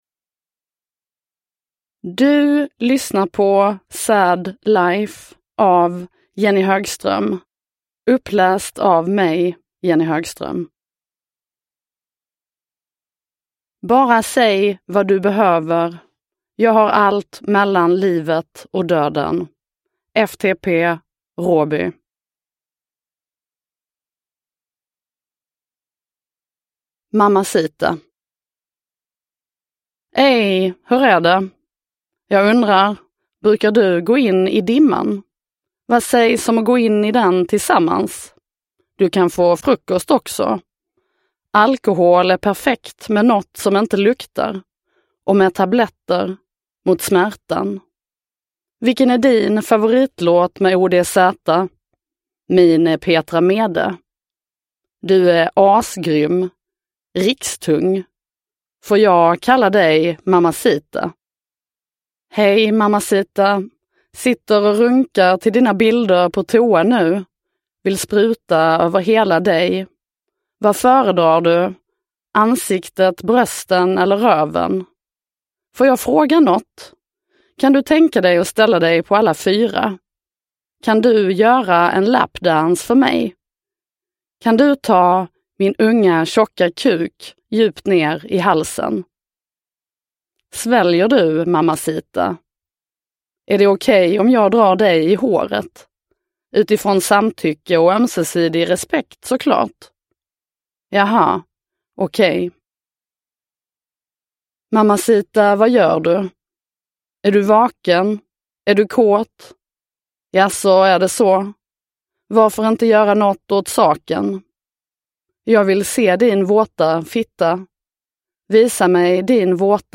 Sad Life – Ljudbok – Laddas ner